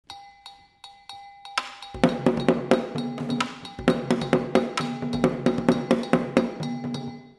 Kinka An Anlo-Ewe Secular Dance-Drumming
Gankogui (bitonal bell)
Atsimevu (lead drum)
Sogo (support drum)
Kidi (support drums)